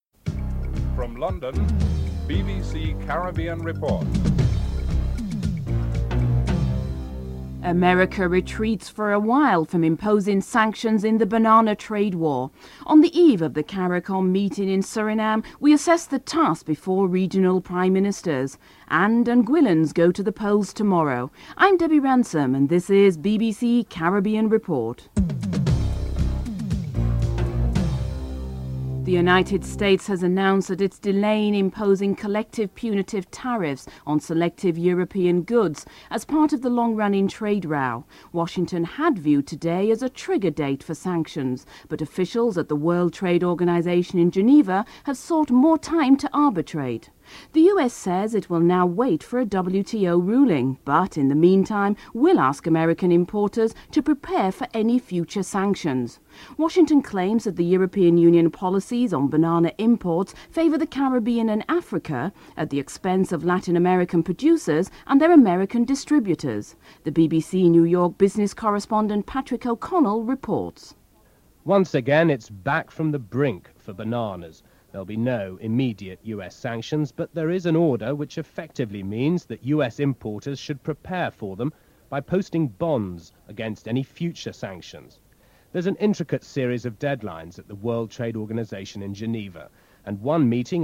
British Finance Minister Gordon Brown outlines a plan to reduce debt and increase aid from industrialised countries and international agencies (07:00 - 07:32)